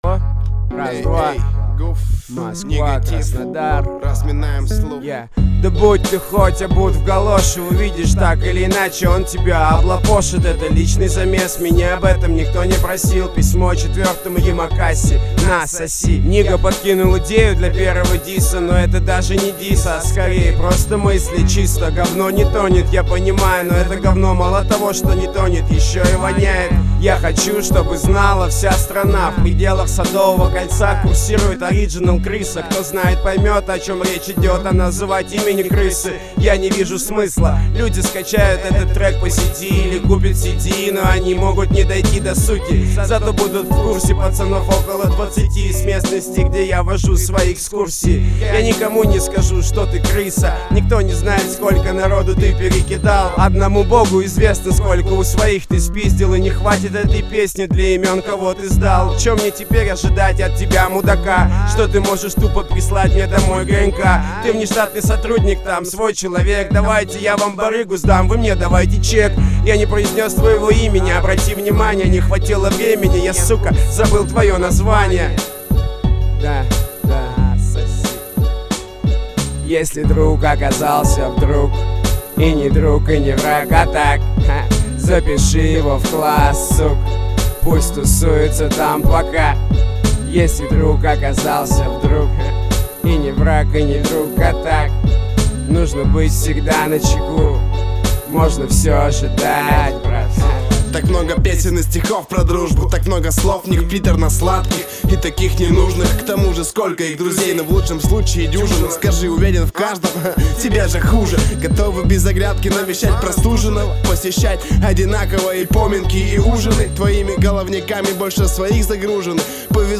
Главная » Файлы » Музыка » РэпЧина